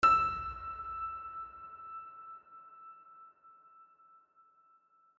piano-sounds-dev
e5.mp3